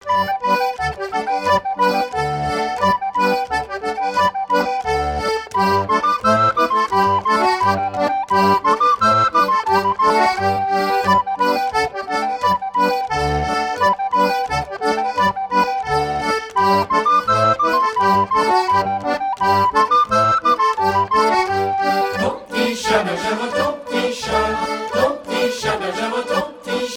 Chants brefs - A danser
danse : scottich trois pas
Pièce musicale éditée